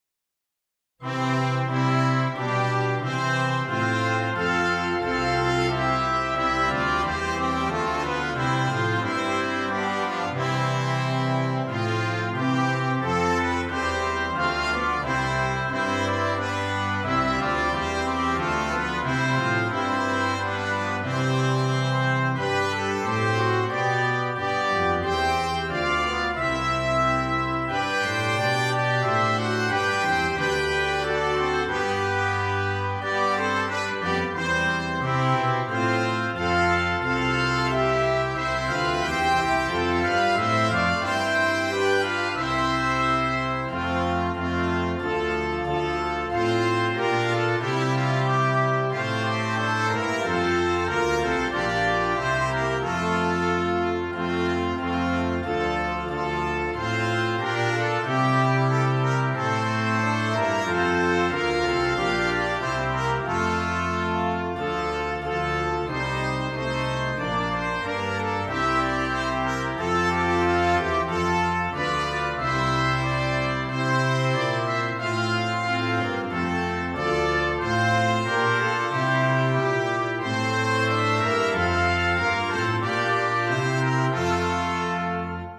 Brass Band
Brass Quintet and Organ